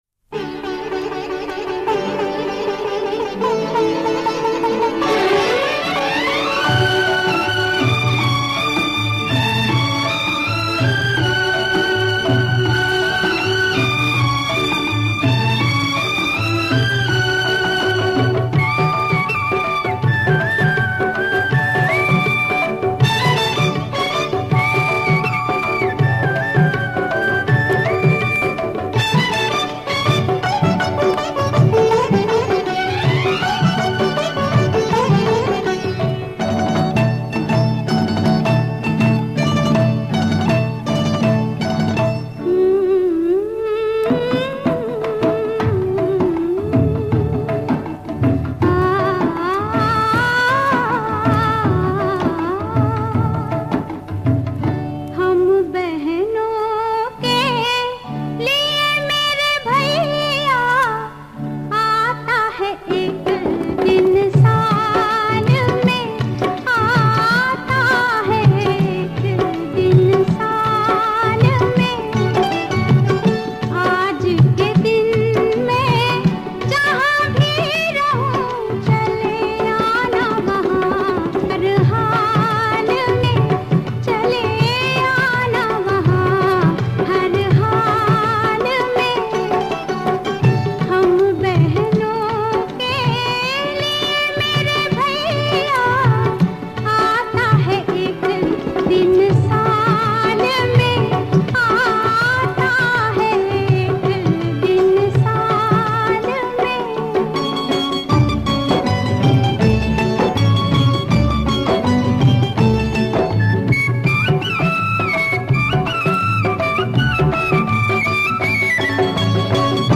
melodious rendition